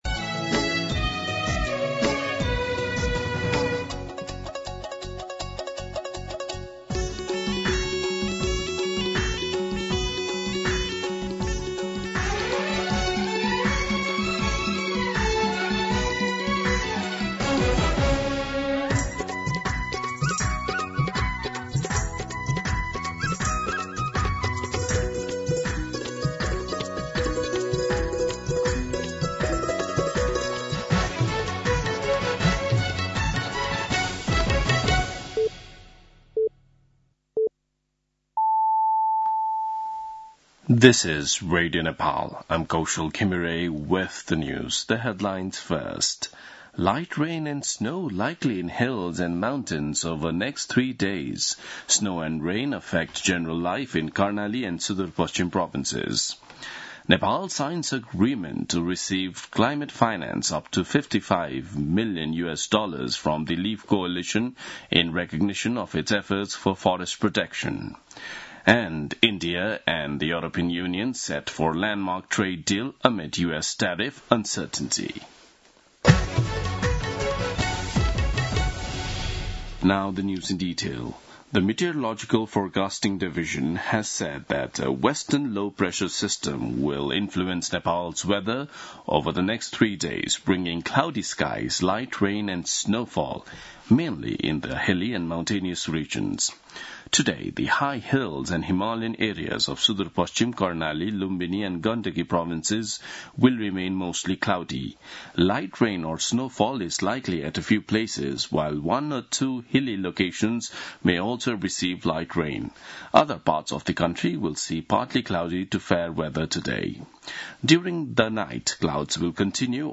दिउँसो २ बजेको अङ्ग्रेजी समाचार : १० माघ , २०८२